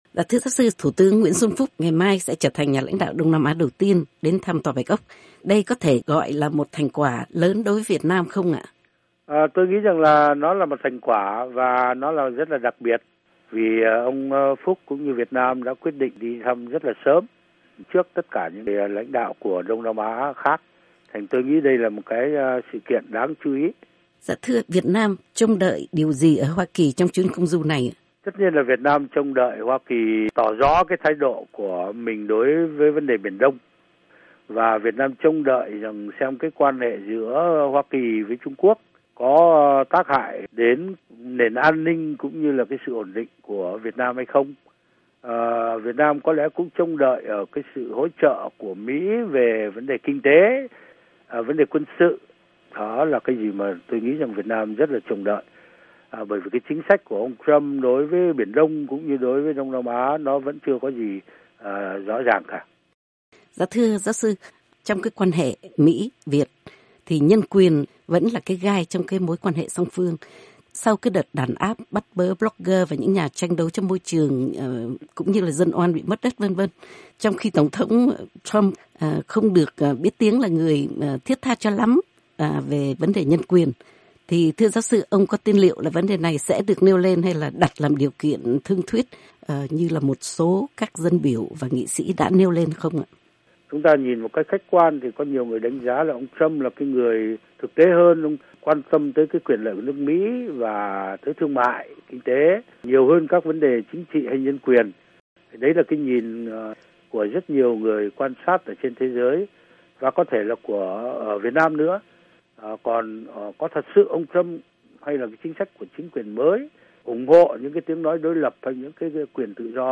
Thủ tướng Phúc ở Washington - phỏng vấn giáo sư Đoàn Viết Hoạt